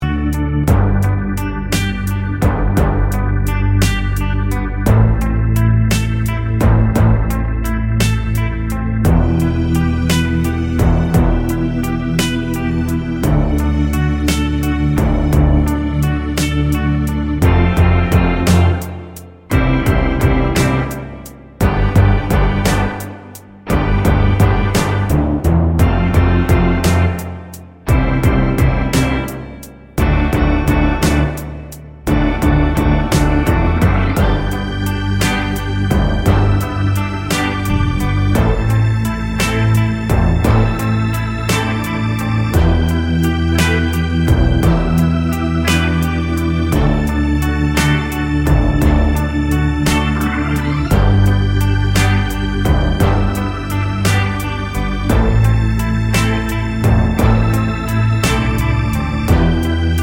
no Backing Vocals R'n'B / Hip Hop 3:43 Buy £1.50